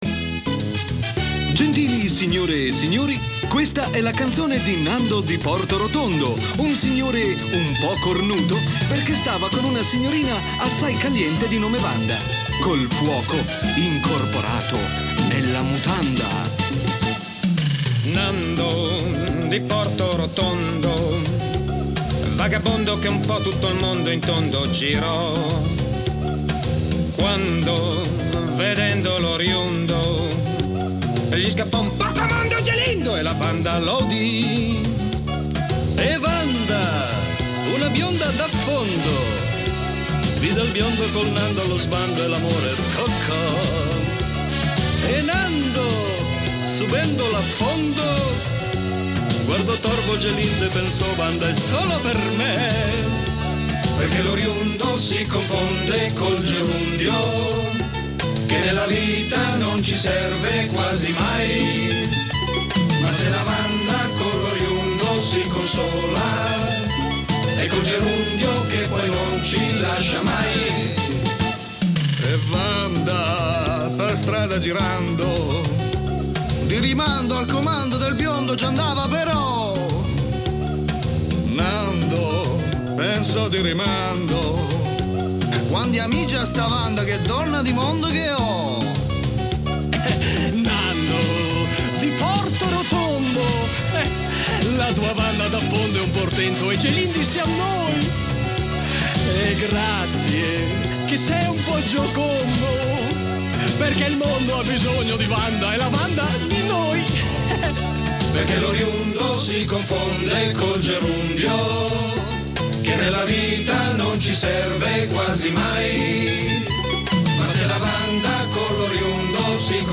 Operazione Sottowest è stato un programma surreale e ironico, a volte irriverente, fino al limite del politicamente scorretto.
Novanta minuti di sano divertimento, senza pretese, con improbabili inviati sempre coinvolti in vicende imbarazzanti e incursioni in studio da parte d’incontenibili ospiti.